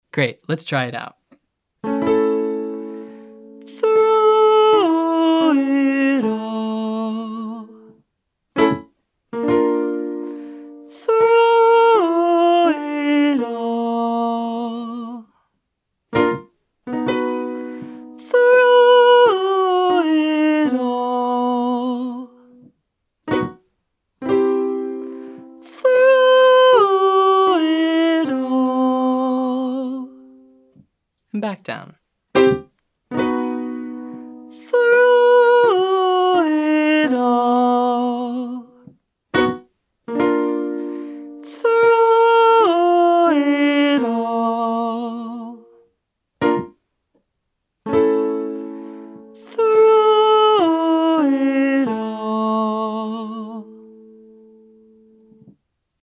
Exercise 2:  Through It All 8-5-3-1
Use the breathiness from the Th sound and eventually come to a very light chest voice at the end.